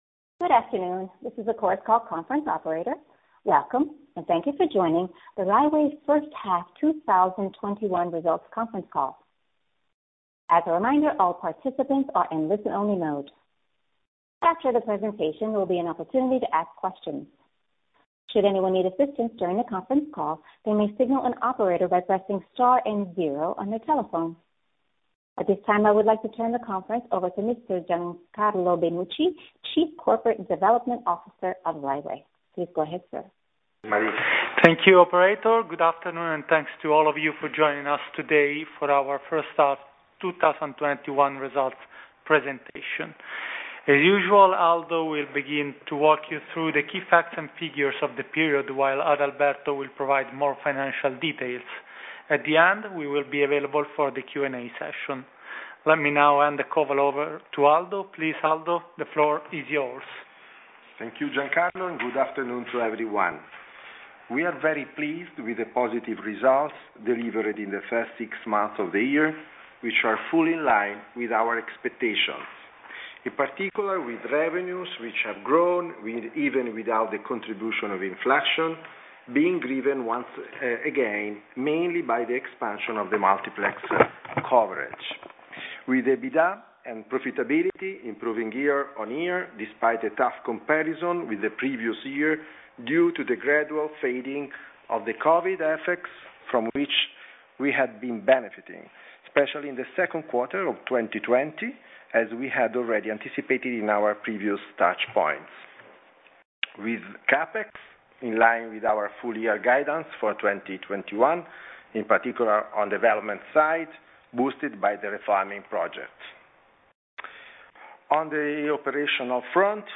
Rai Way Conference Call